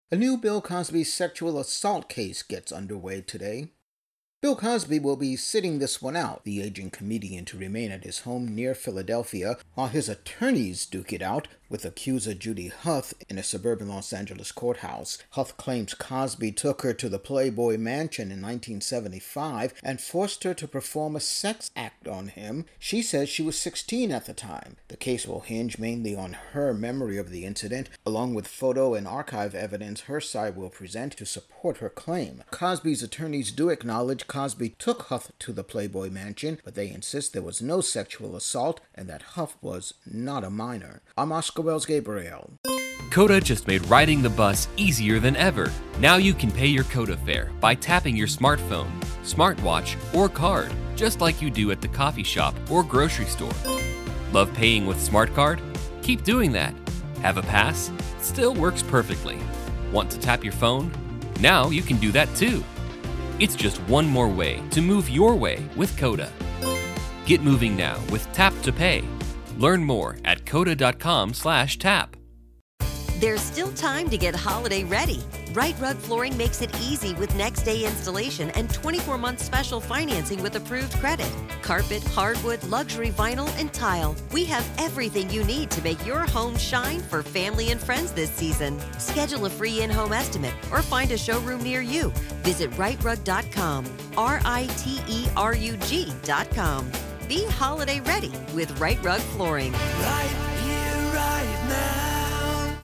Intro+voicer